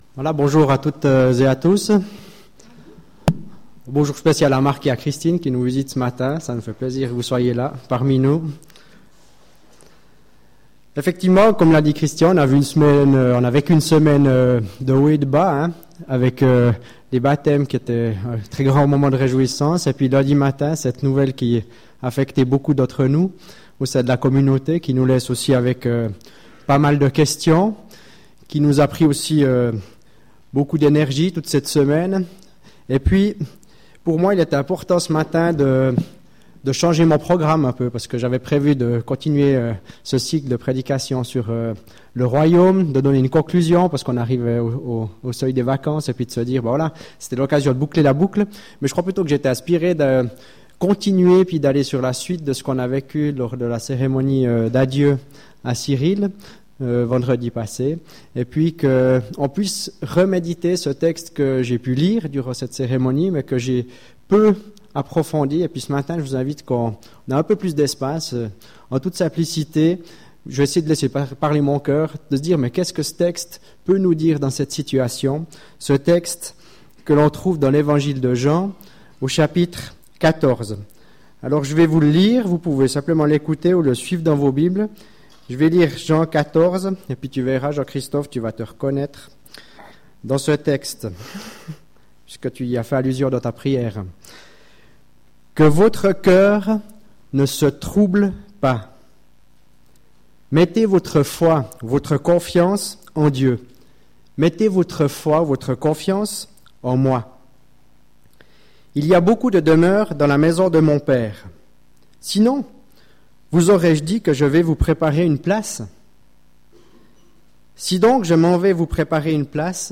Culte du 3 juillet 2016